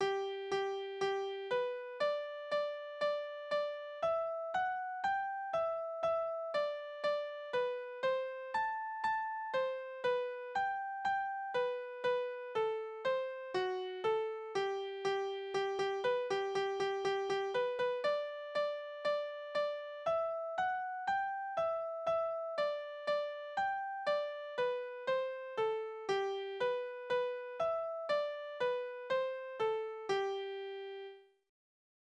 Wiegenlieder: So fahren wir
Tonart: G-Dur
Taktart: 4/4
Tonumfang: kleine Dezime